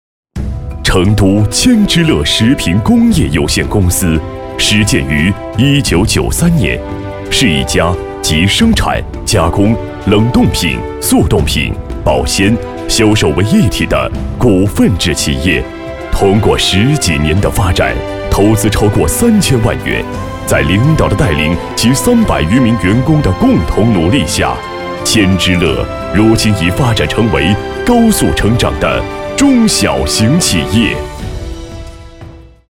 配音风格： 舒缓